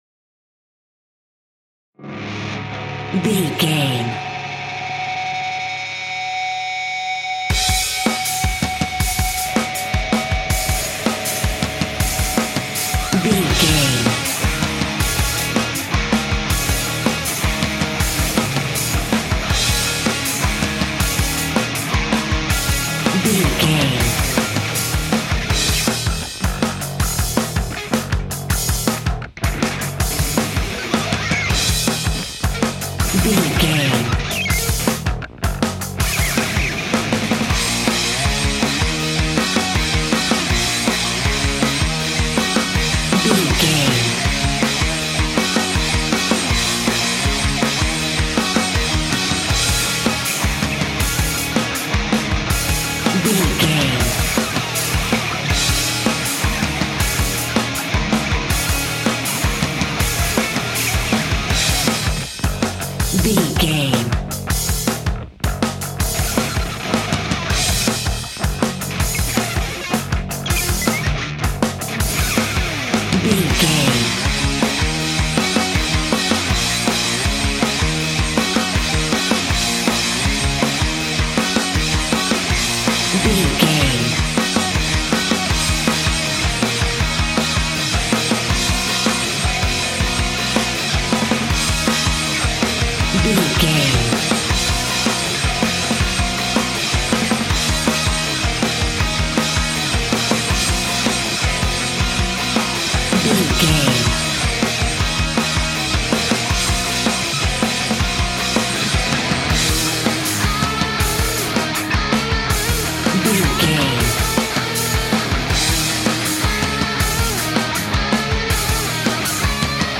Aeolian/Minor
E♭
hard rock
heavy metal
blues rock
distortion
instrumentals
rock guitars
Rock Bass
heavy drums
distorted guitars
hammond organ